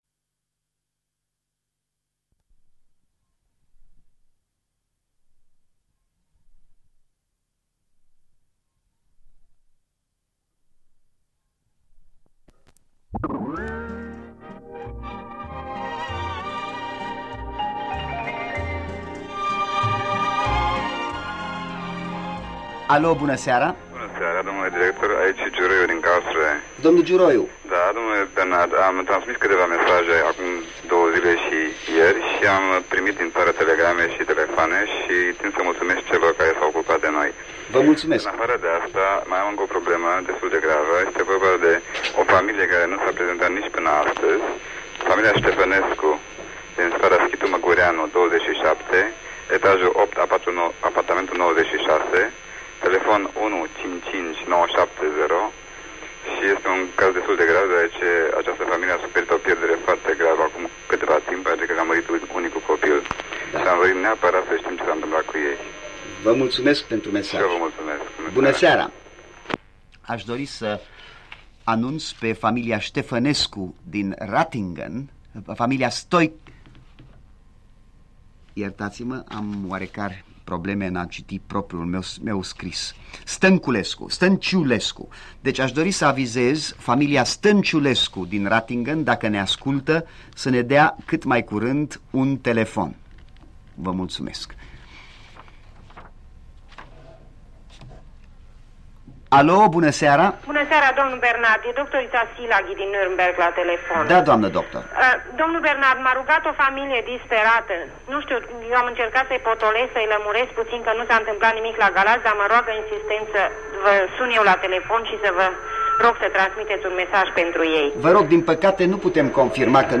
1977 - În direct cu ascultătorii din România în zilele cutremurului